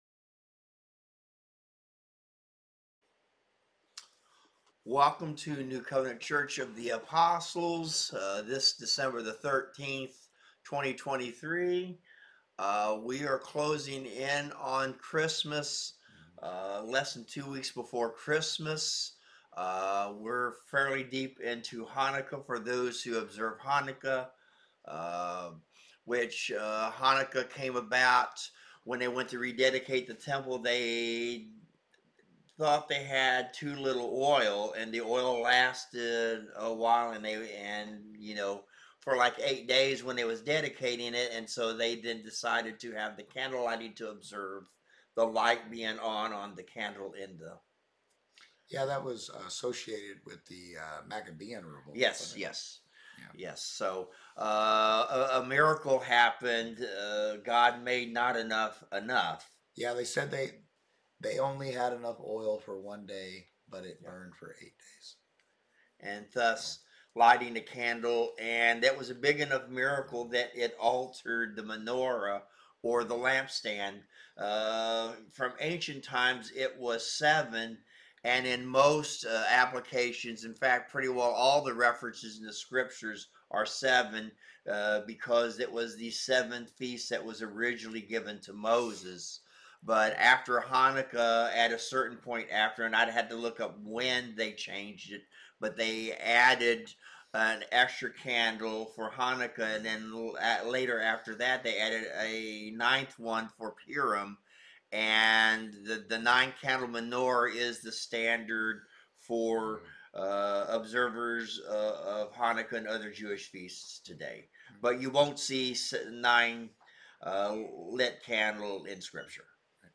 Service Type: Wednesday Word Bible Study